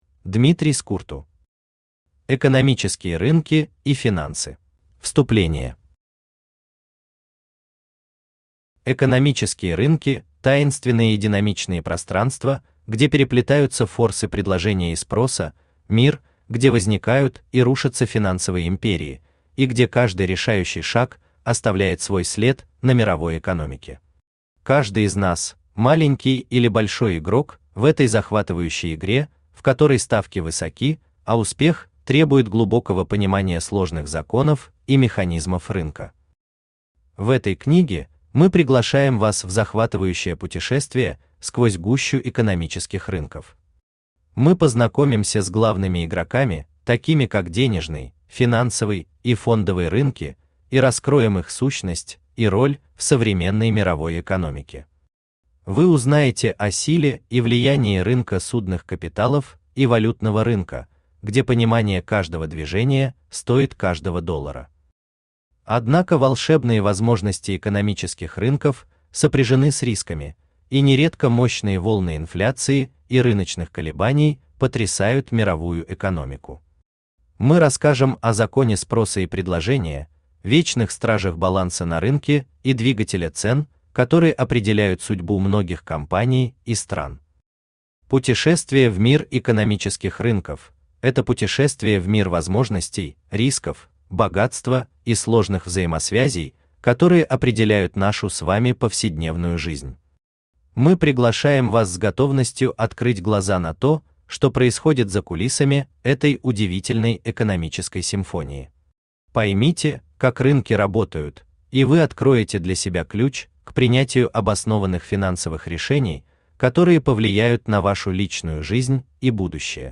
Аудиокнига Экономические рынки и финансы | Библиотека аудиокниг
Aудиокнига Экономические рынки и финансы Автор Дмитрий Скурту Читает аудиокнигу Авточтец ЛитРес.